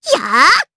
Rehartna-Vox_Attack3_jp.wav